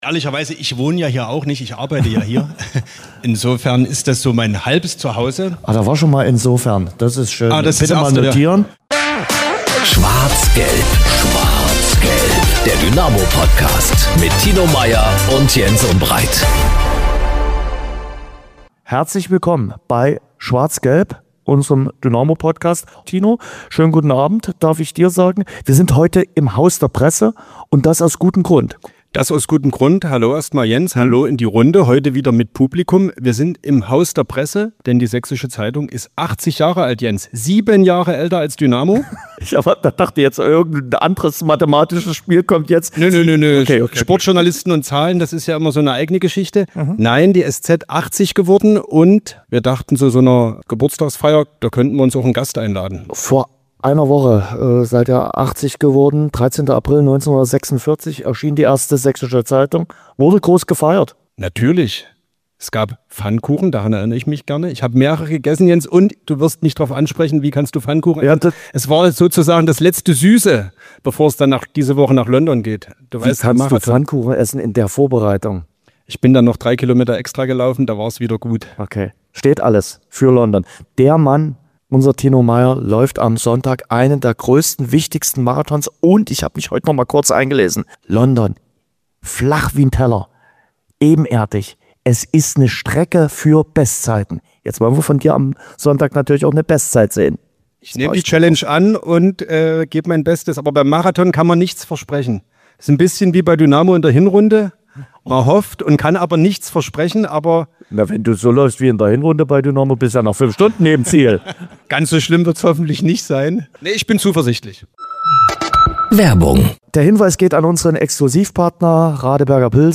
#169 Vincent Vermeij im Interview: Tore, schlaflose Nächte & Wachstumsschub ~ SCHWARZ GELB - Der Dynamo-Podcast Podcast